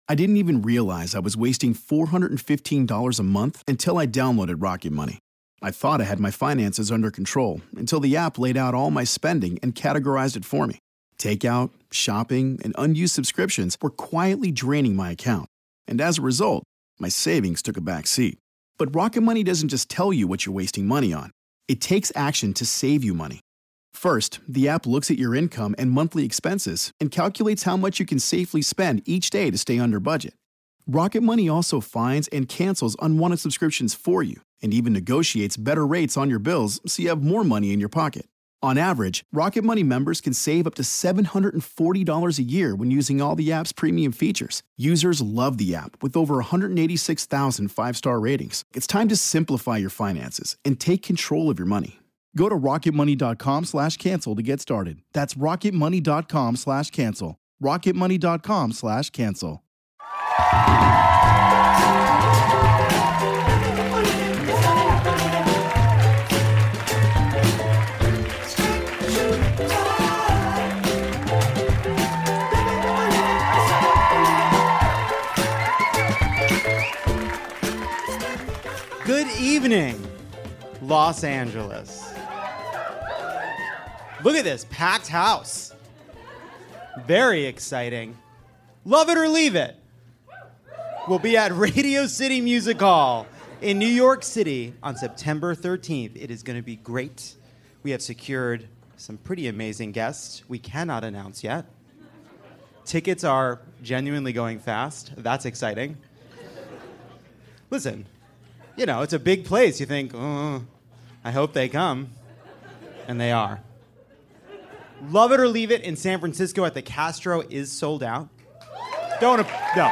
Governor Jay Inslee stops by to discuss climate change, his disagreement with Joe Biden and Andrew Yang, whether he knows what it means to be called a "snack," and a shocking revelation regarding Harry Potter.